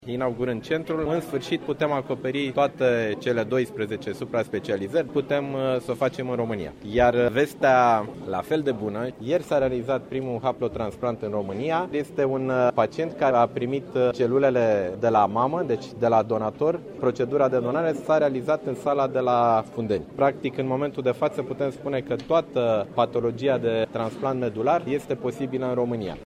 Prezent la inaugurare, ministrul Sanatatii, Nicolae Banicioiu, a declarat ca avem cel mai mare centru de transplant renal din Europa: